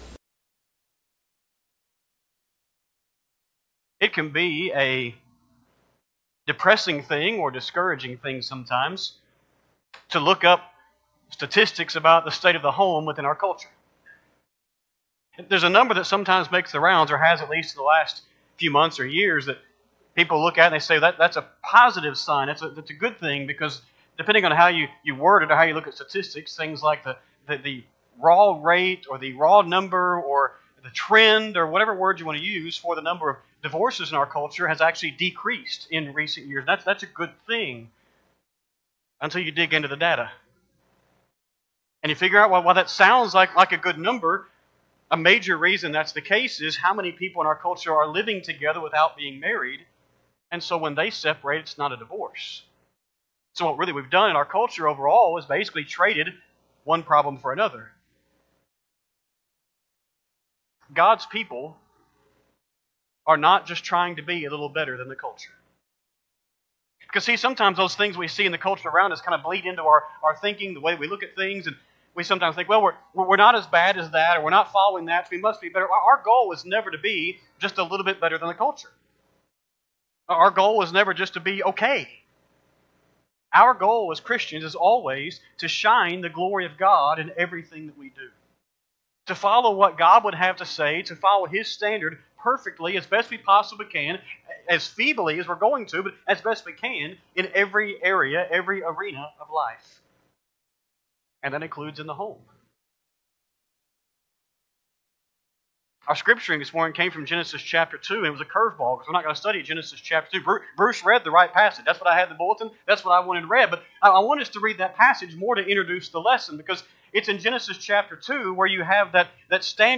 Culture , Family , Rejection , Spiritual Growth , Sunday AM Sermon